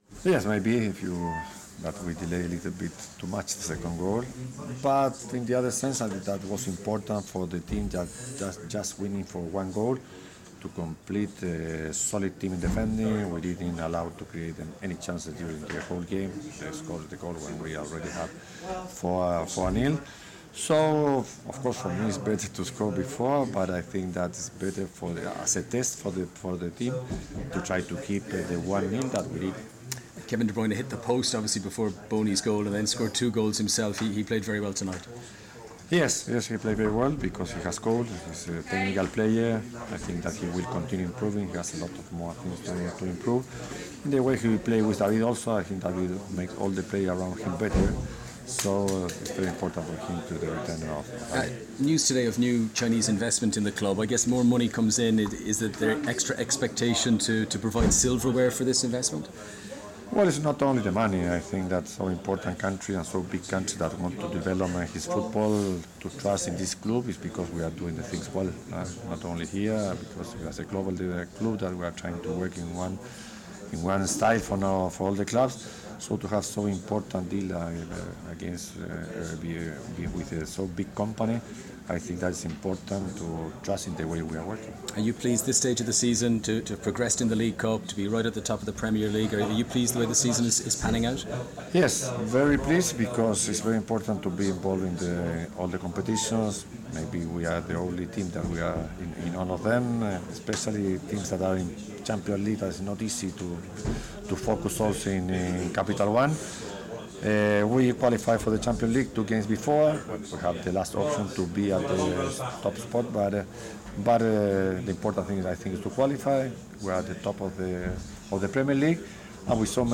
Manchester City manager Manuel Pellegrini says he's pleased to still be the only Premier League team still to be involved in all competitions including the League Cup despite an average of 6 players out per game.